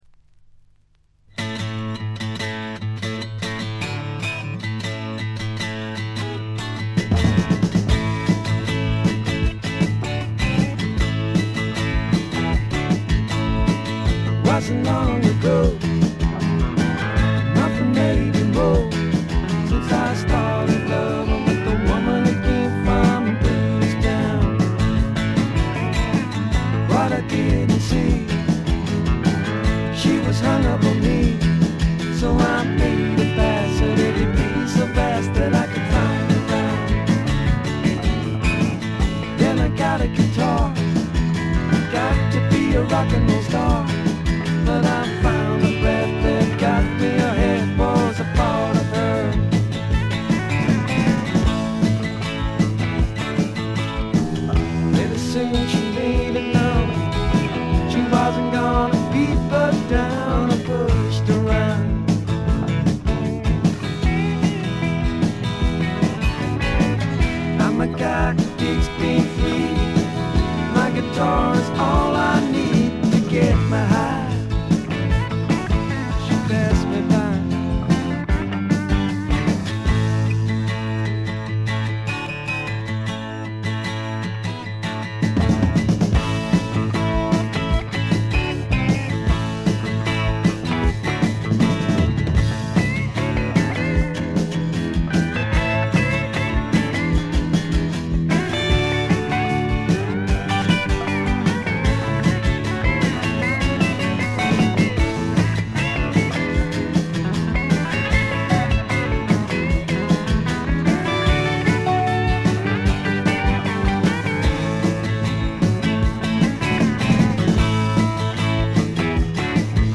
ほとんどノイズ感無し。
聴くたびにご機嫌なロックンロールに身をゆだねる幸せをつくずく感じてしまいますね。
試聴曲は現品からの取り込み音源です。